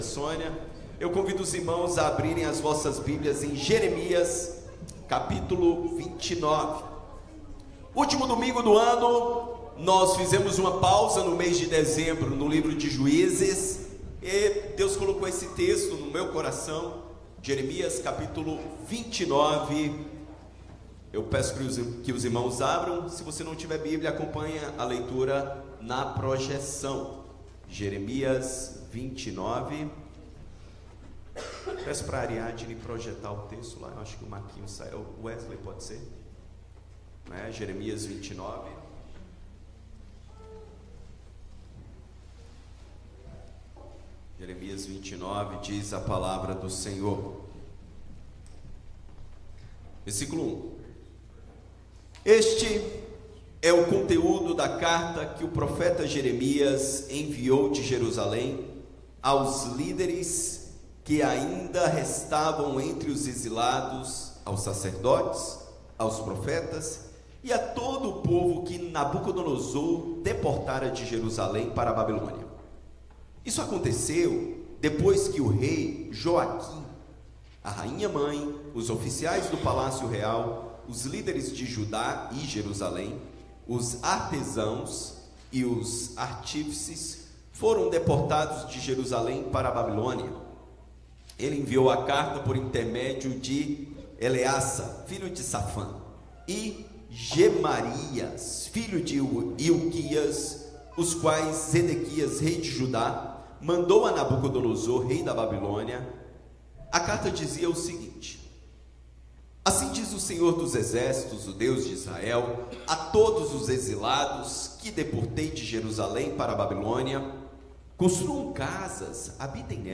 Sermons from Huntingdon Valley Presbyterian Church